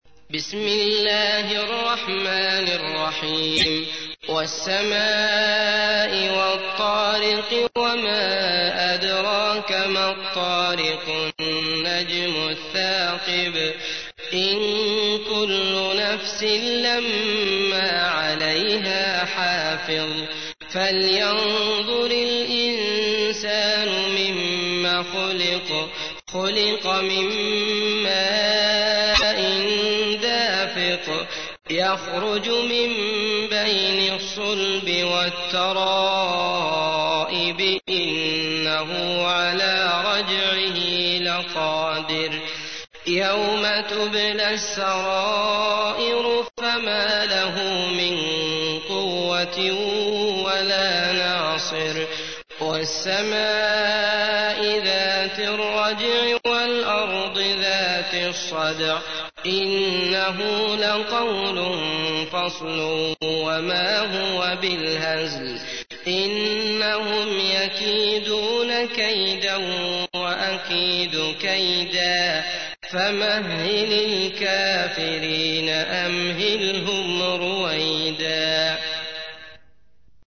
تحميل : 86. سورة الطارق / القارئ عبد الله المطرود / القرآن الكريم / موقع يا حسين